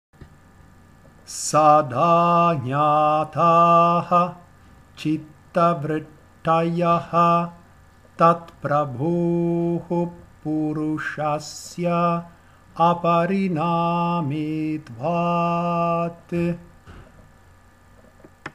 Kaivalya padah canto vedico – Yoga Saram Studio